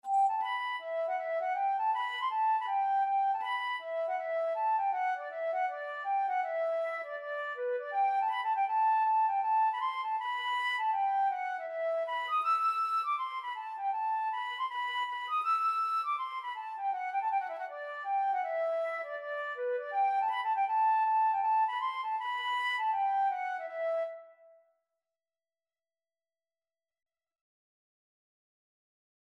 Traditional Trad. I Dreamt of My Love (Irish Folk Song) Flute version
Free Sheet music for Flute
E minor (Sounding Pitch) (View more E minor Music for Flute )
4/4 (View more 4/4 Music)
Traditional (View more Traditional Flute Music)